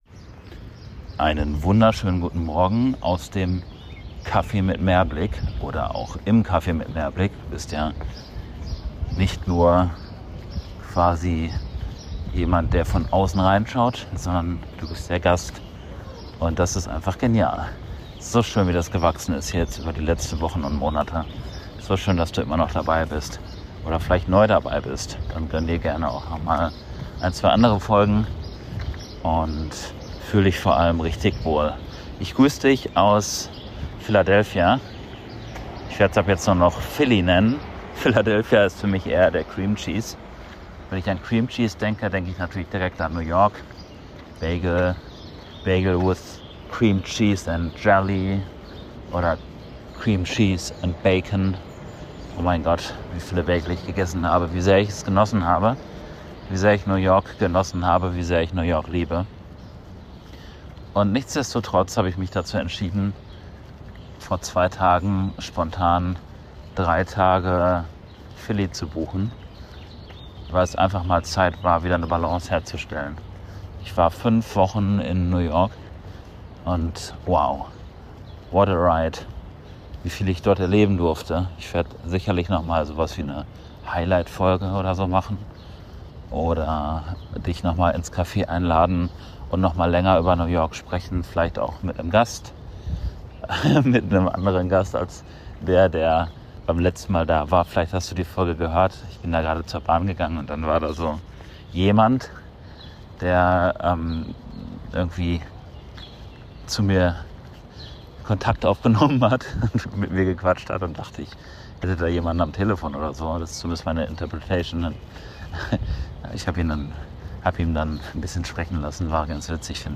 Ich darf fuer drei Tage in Philadelphia sein und melde mich von einem morgendlichen Spaziergang durchs gerade aufwachende, magische Philly. Was das mit zwei Tattoos von mir zu tun hat, hörst du nur hier :)